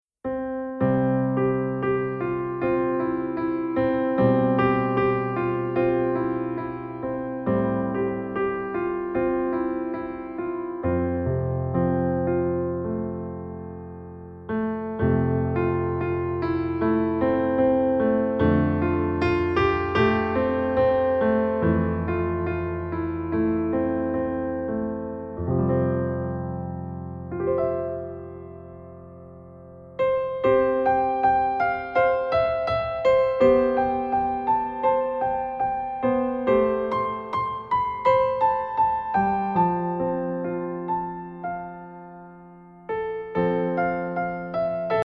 Mp3 Instrumental Song Download